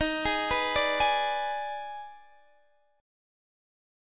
Listen to EbM7sus4#5 strummed